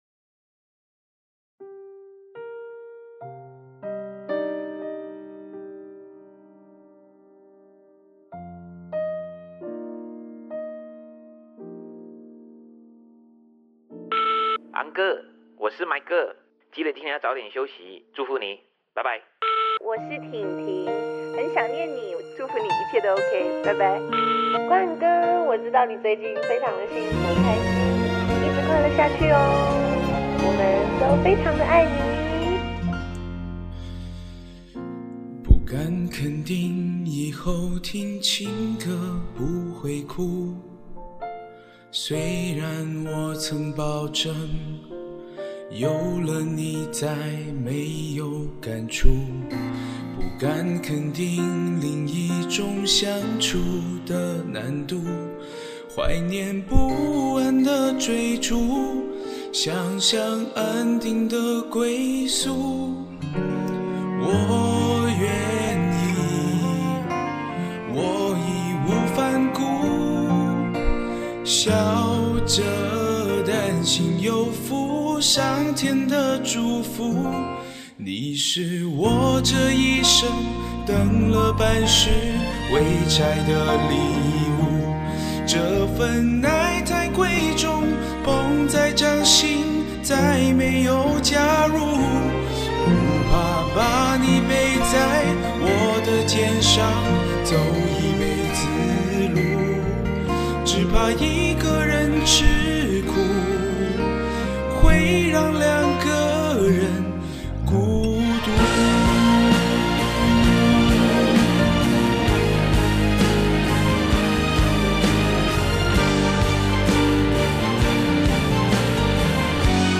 我也不是很清楚，找到了就唱一下 后期还是不会做。
有几个地方唱叉了。。。不好意思。